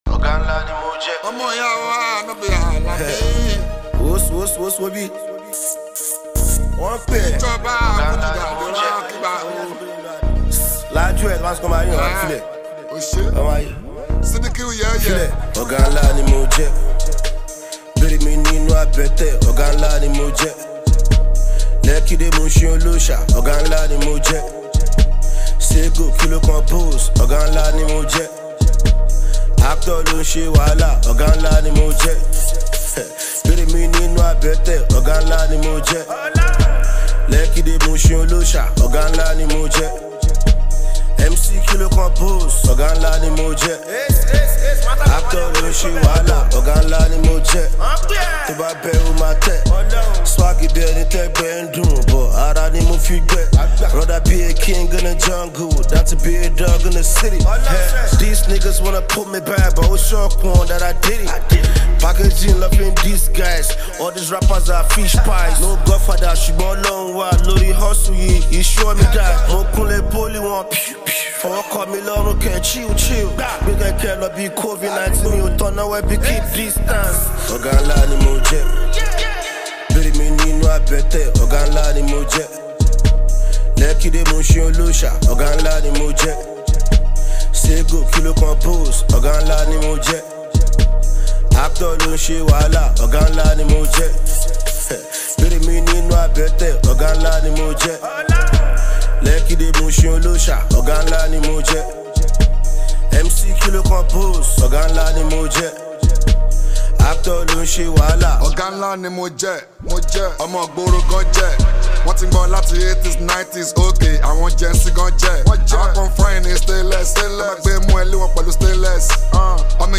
hip-hop and Fuji.